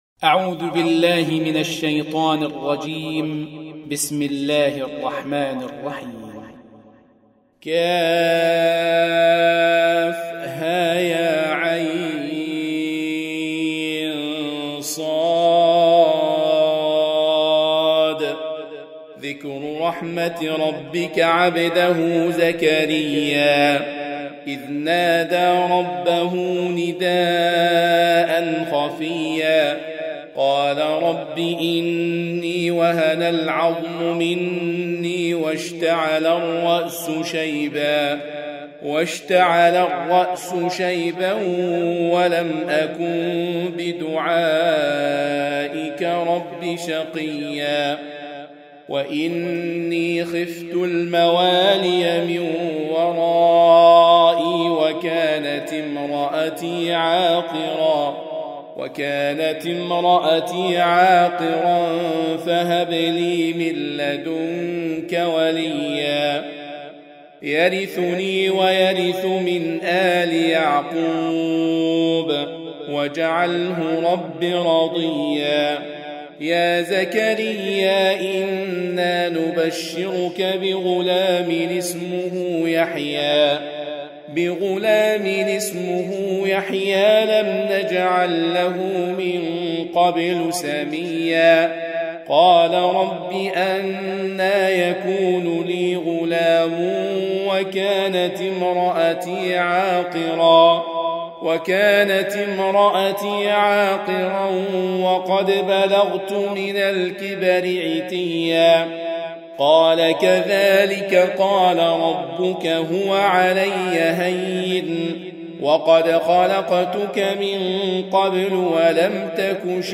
Surah Sequence تتابع السورة Download Surah حمّل السورة Reciting Murattalah Audio for 19. Surah Maryam سورة مريم N.B *Surah Includes Al-Basmalah Reciters Sequents تتابع التلاوات Reciters Repeats تكرار التلاوات